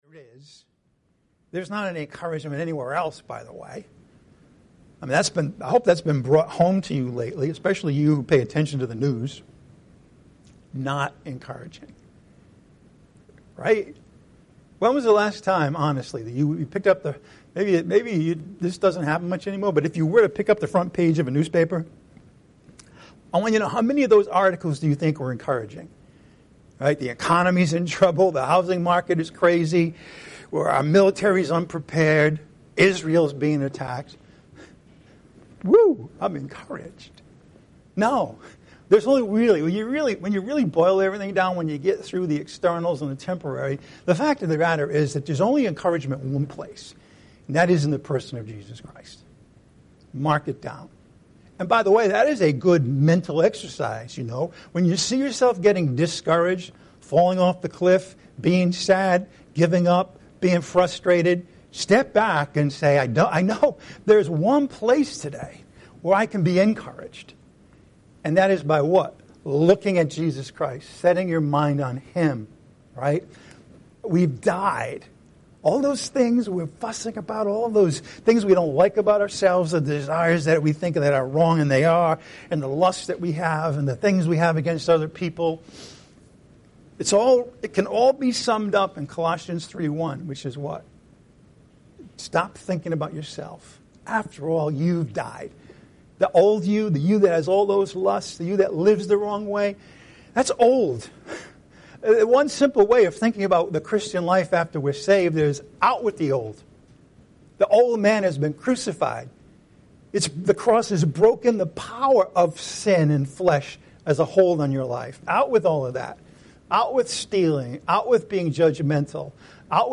Lighthouse Bible Church (LBC) is a no-nonsense, non-denominational, grace oriented and Bible centered Christian church.